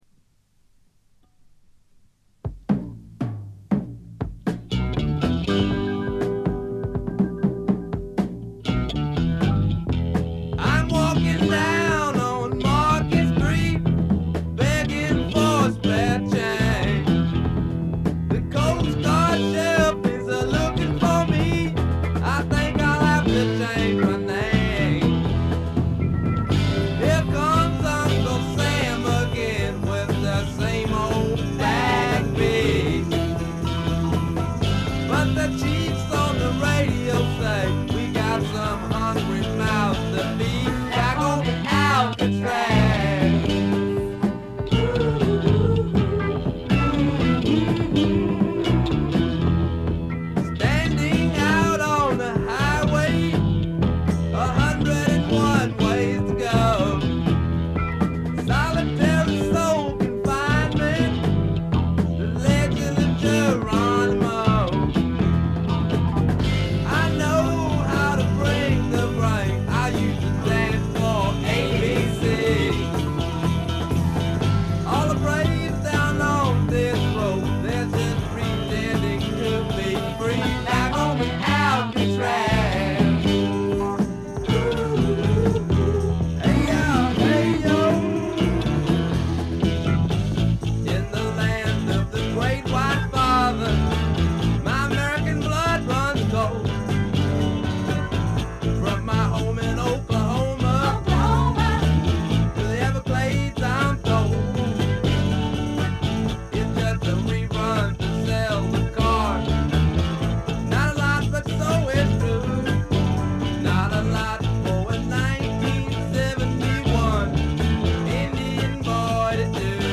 ほとんどノイズ感無し。
いうまでもなく米国スワンプ基本中の基本。
試聴曲は現品からの取り込み音源です。